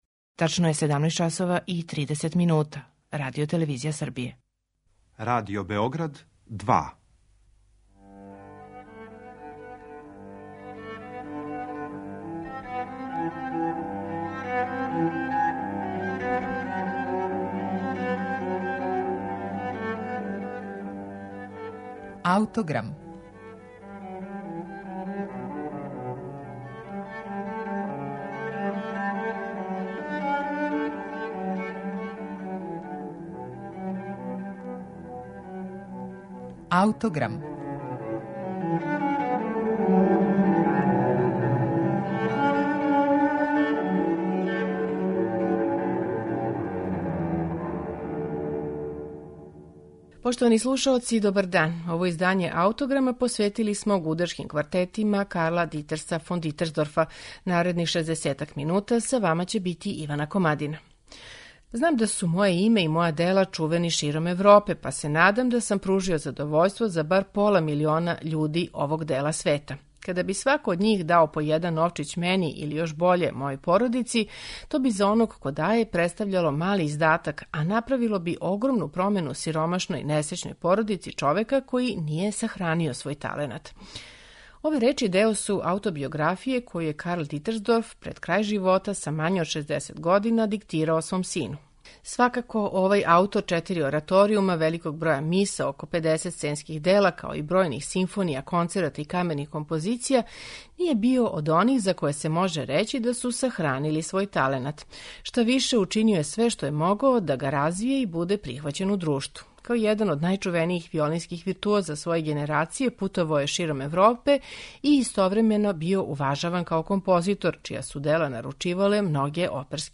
Гудачки квартети Карла Дитерса фон Дитерсдорфа
Оно што је извесно јесте да је аутор овде користио различите облике тонске текстуре, врло суптилне динамичке промене, а мелодијске деонице најчешће водио у форми дијалога. У неким моментима подсећао је на Хајднове зреле квертете, посебно када је употребљавао дугачке педалне мотиве или изненадне модулације у удаљене тоналитете.
Слушаћете их у интерпретацији квартета Sharon.